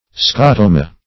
Search Result for " scotoma" : Wordnet 3.0 NOUN (1) 1. an isolated area of diminished vision within the visual field ; The Collaborative International Dictionary of English v.0.48: Scotoma \Sco*to"ma\, n. [L.]